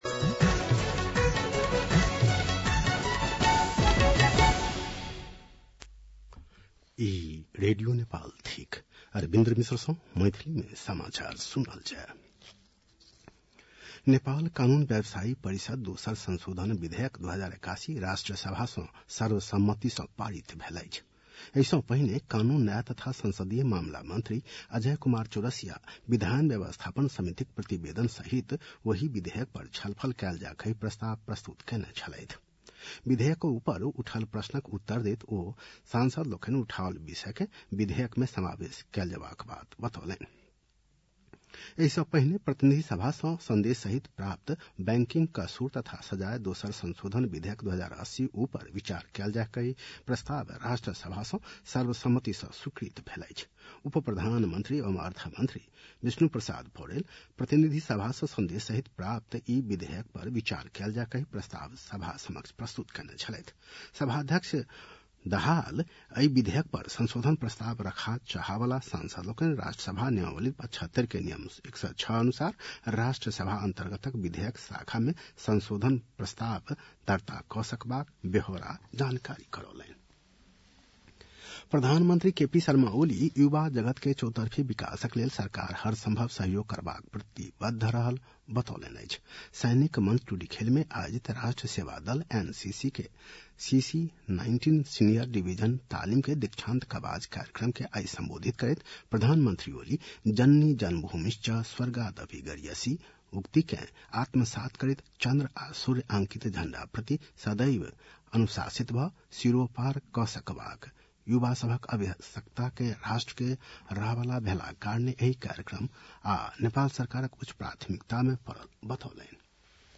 मैथिली भाषामा समाचार : १९ फागुन , २०८१